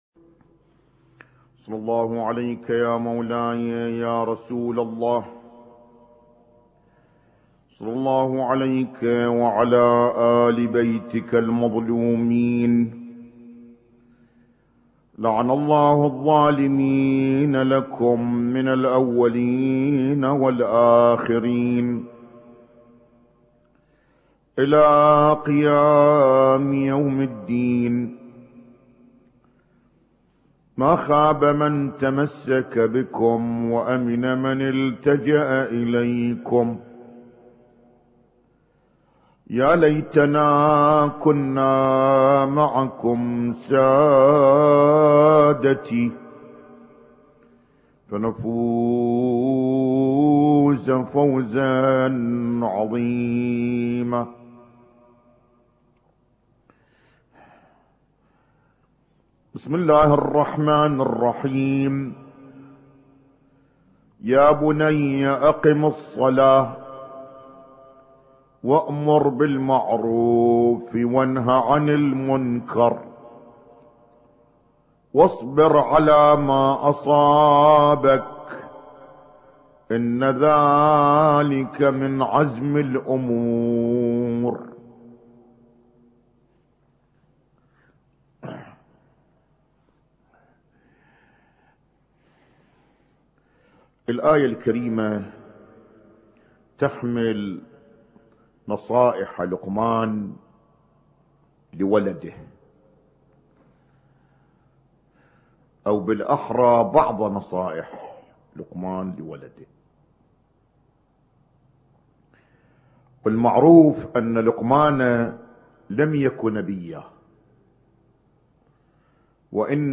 Husainyt Alnoor Rumaithiya Kuwait
اسم التصنيف: المـكتبة الصــوتيه >> المحاضرات >> المحاضرات الاسبوعية ما قبل 1432